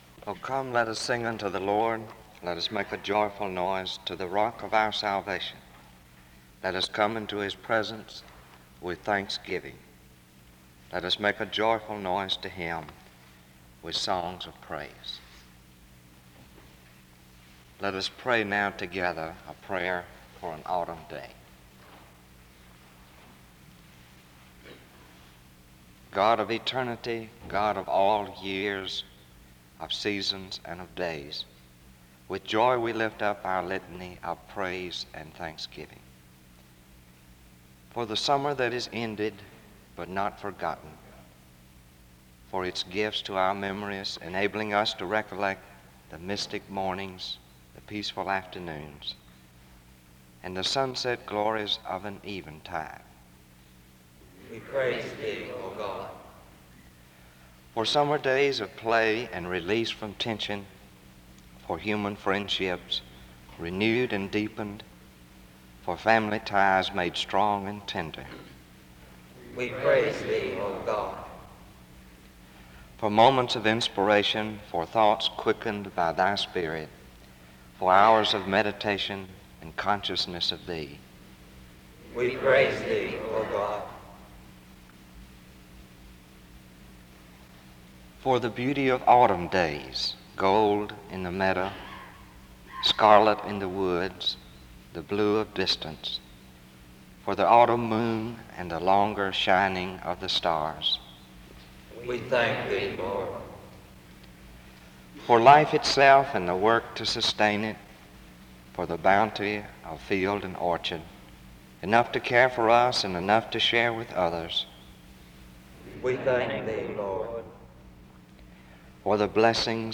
SEBTS_Chapel_Student_Service_1967-11-28.wav